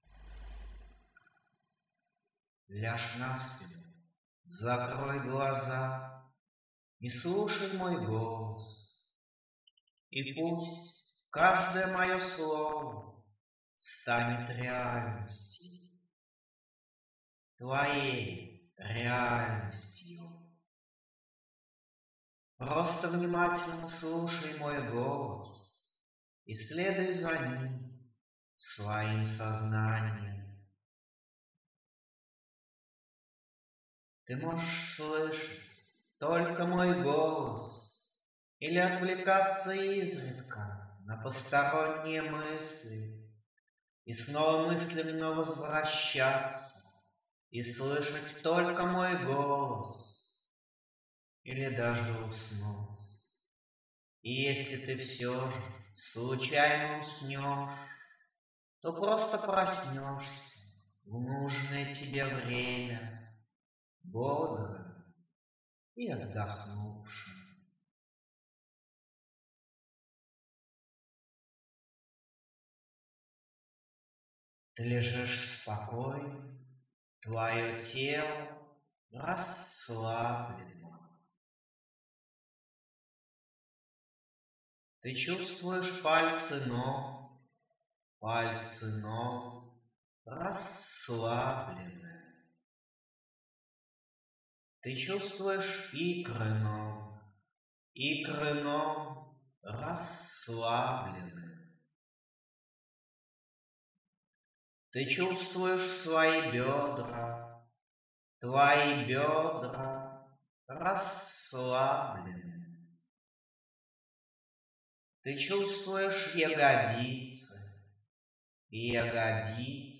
Аудиомедитации